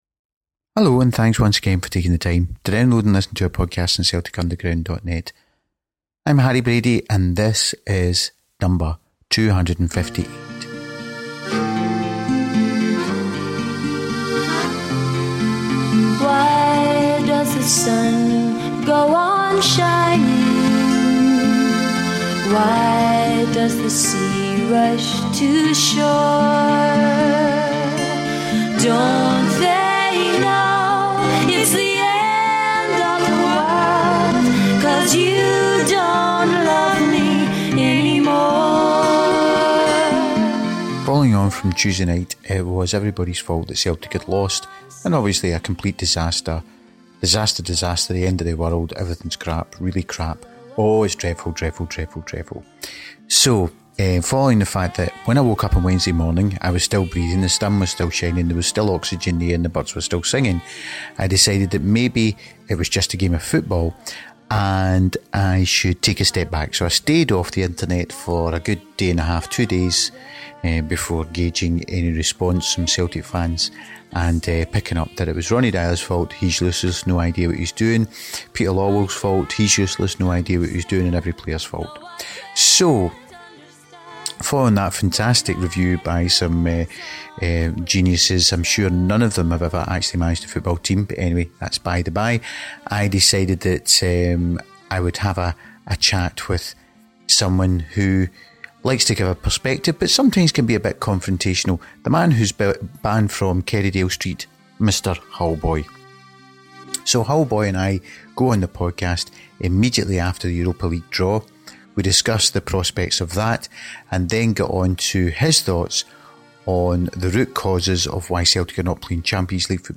with his calming Hull tones to provide his overview.